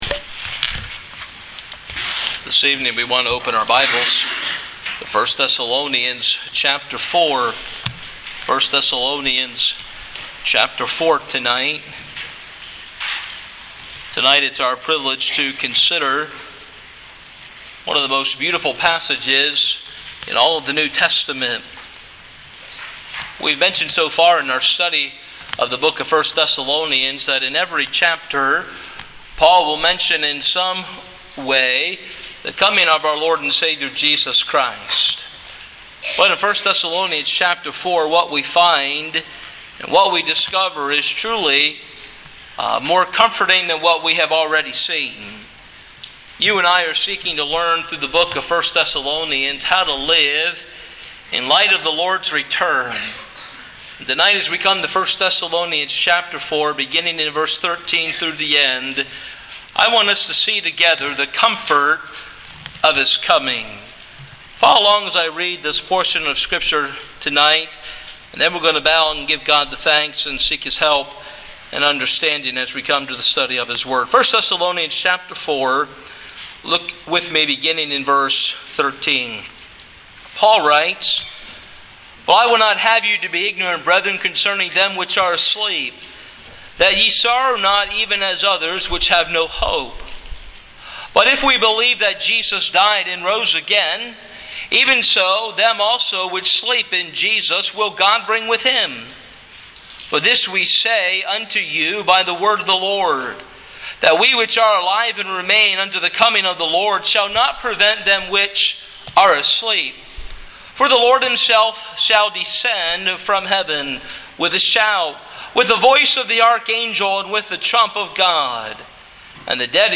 Sermon MP3